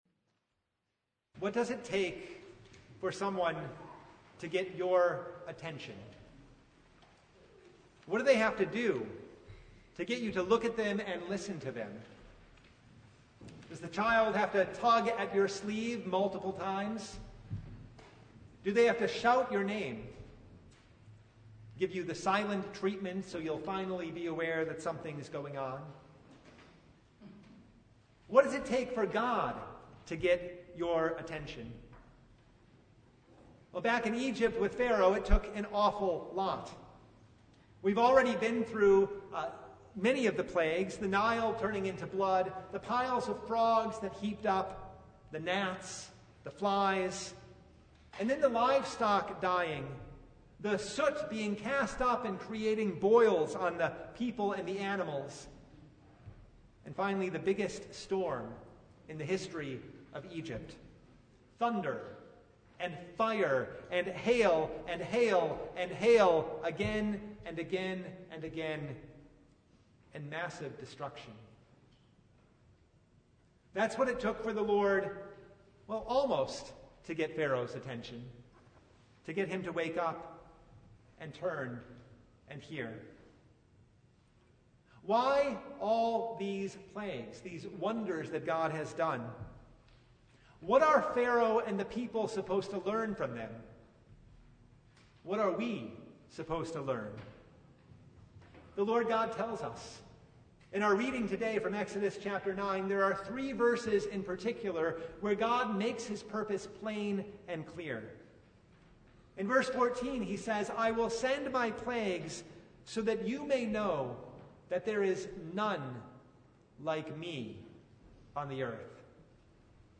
Exodus 9:1-35 Service Type: Lent Midweek Noon Topics: Sermon Only